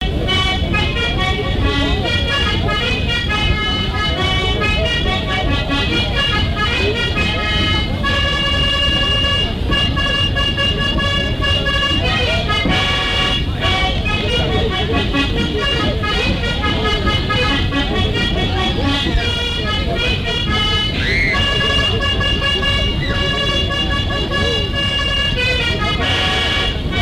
lors d'une kermesse
Pièce musicale inédite